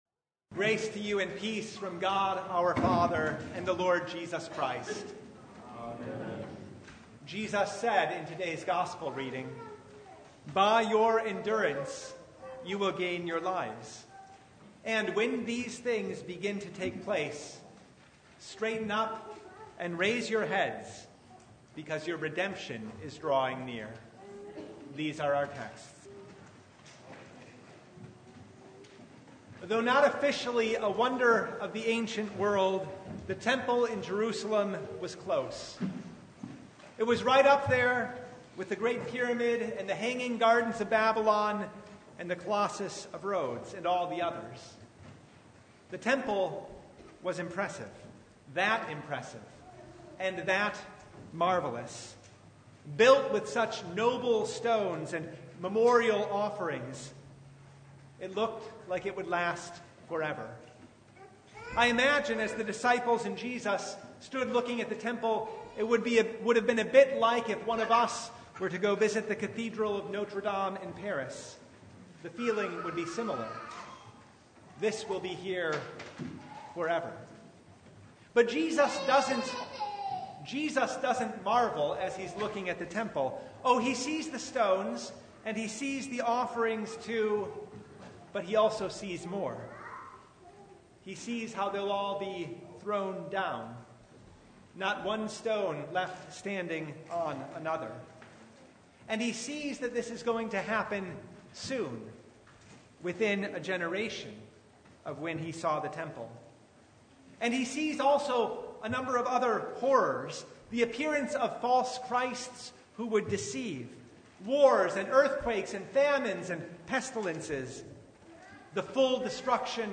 Luke 21:5-28 Service Type: Sunday Bible Text